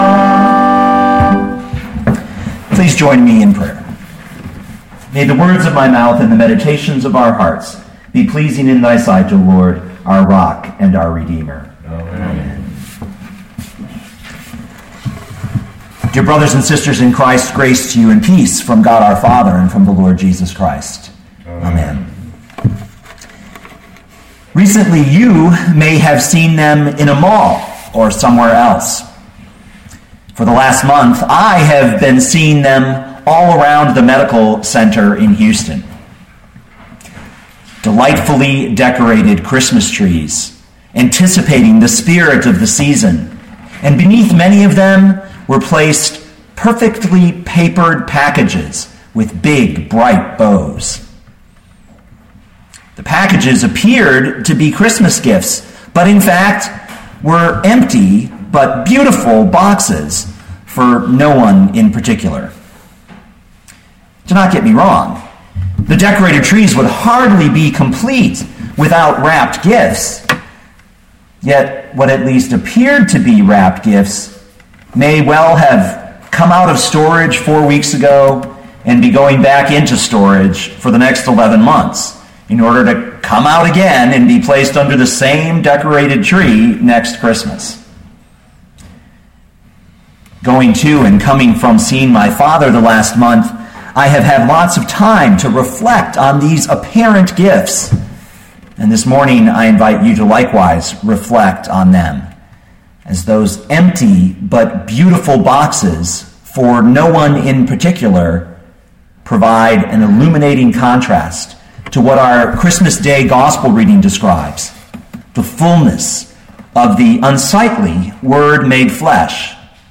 2013 John 1:1-18 Listen to the sermon with the player below, or, download the audio.